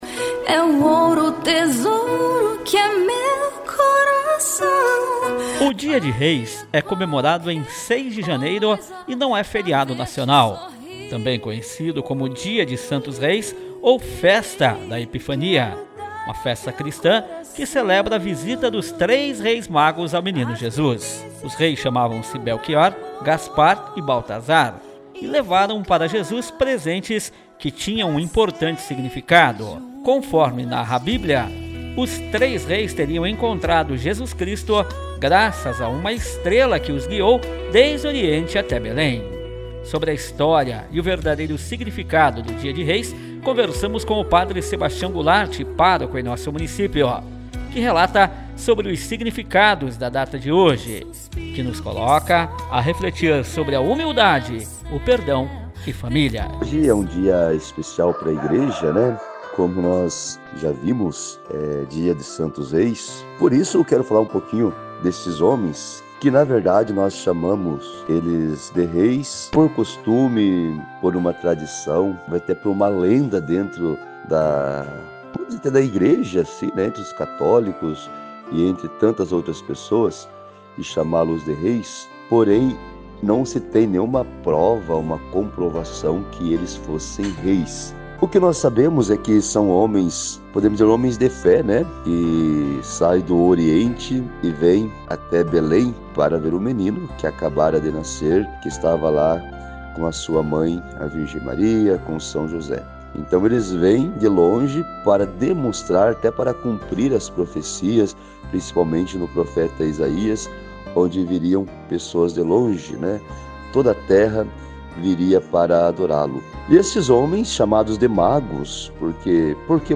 Matéria em áudio